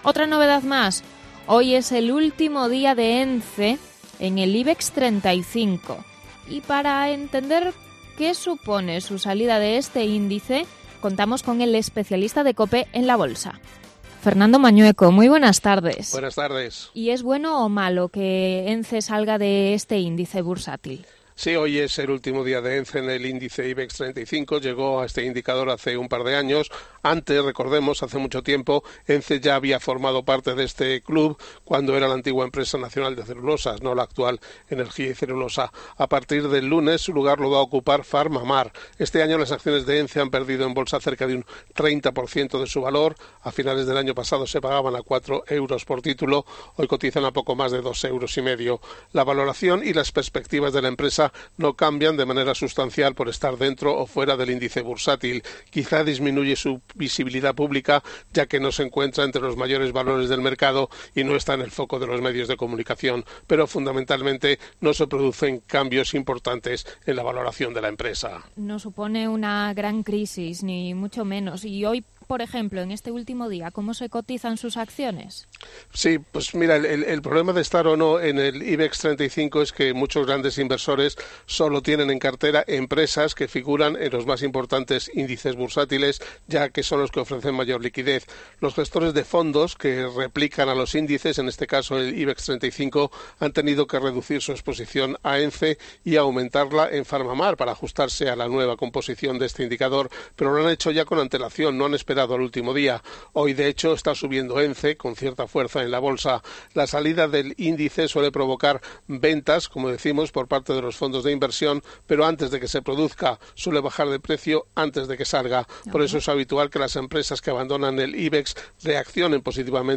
Análisis de la salida de ENCE del IBEX 35